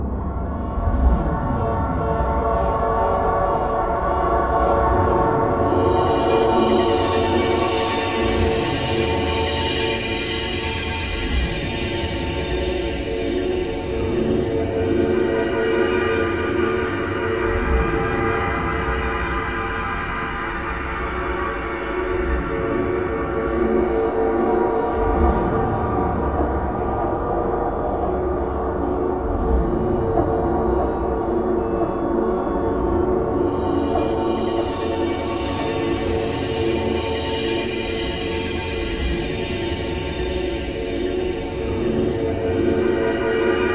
SPACYSEQ.wav